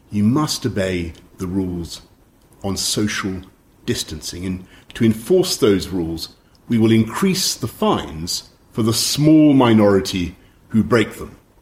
Next -and it seems like a joke, right now, really- British Prime Minister Boris Johnson addressing the nation and admonishing people about the consequences of not keeping the rules during the coronavirus pandemic. Johnson, on the other hand, clearly reduces the diphthong to a schwa /əˈbeɪ/.
obey-weakened-Boris-Johnson-addressing-the-nation.mp3